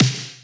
043_PIANOFART_2.wav